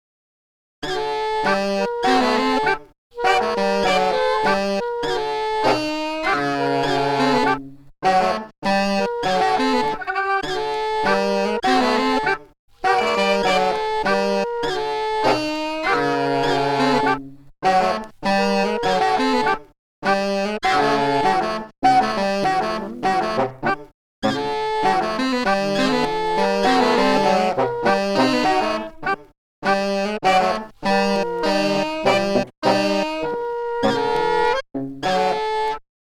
'bach-played-by-Roscoe-Mitchell' soundfile